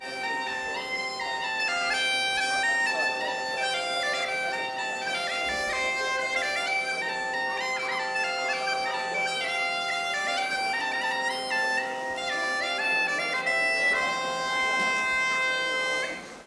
Bagpipes
Bumped into a street performer playing the bagpipes, in Ramsgate’s High Street. Nothing drones like bagpipes do.